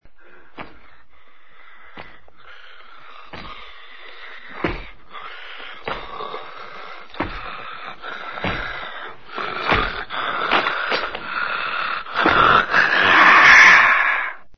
Tonos gratis para tu telefono – NUEVOS EFECTOS DE SONIDO DE AMBIENTE de ESPANTOTERROR HORRO 13
Ambient sound effects
EspantoTerror_HORRO_13.mp3